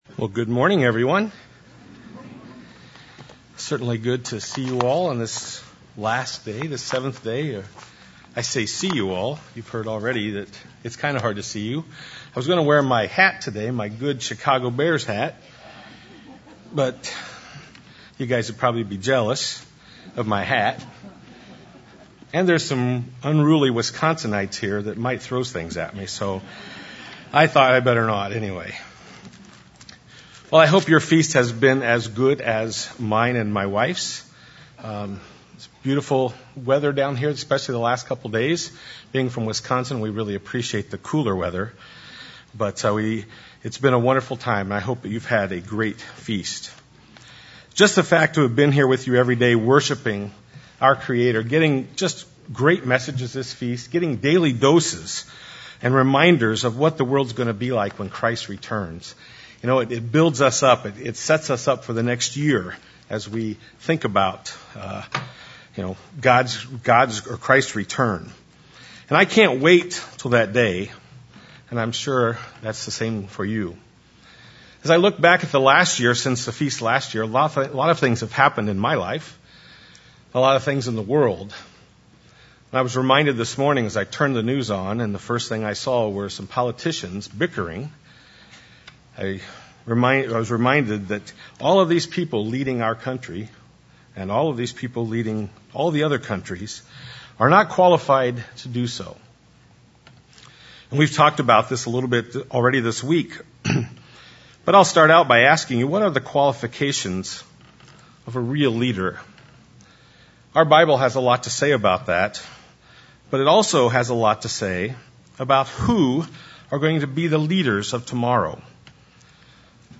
This sermon was given at the Branson, Missouri 2017 Feast site.